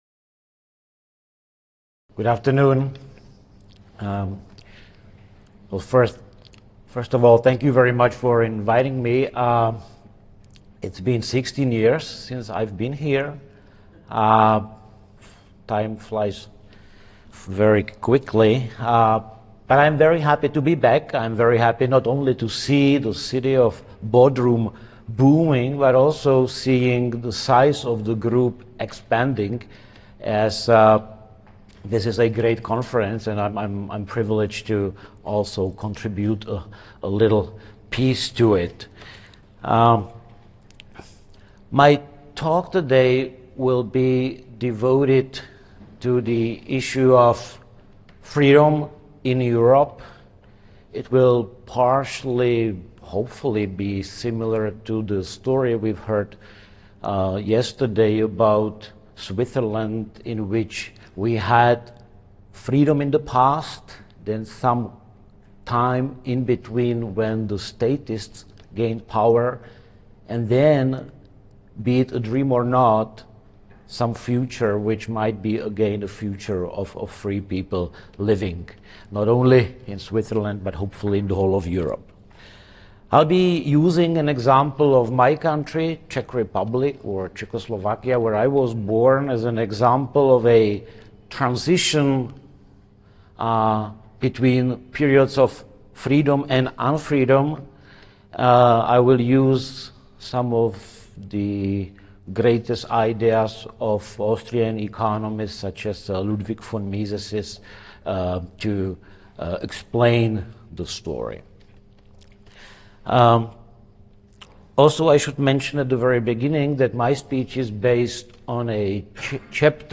This talk is from the recently-concluded Seventeenth Annual (2023) Meeting of the Property and Freedom Society, Sept. 21-26, 2023.